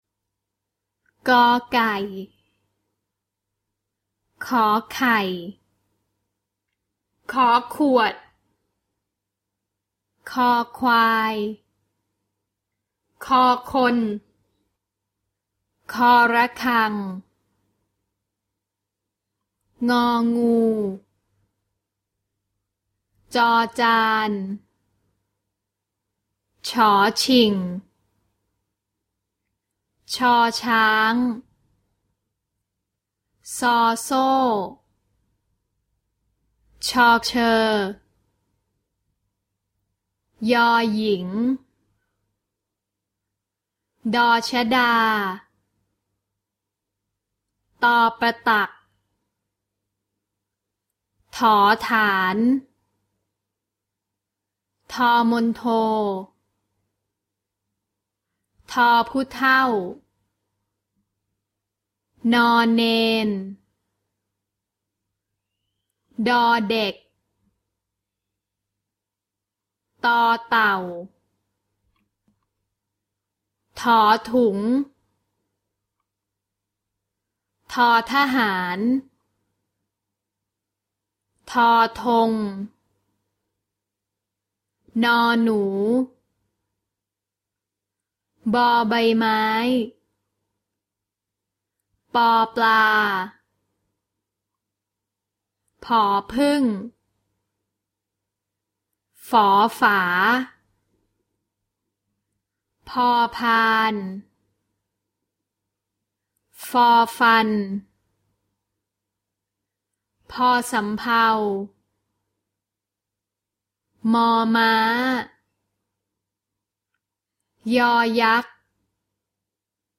Thai alphabet song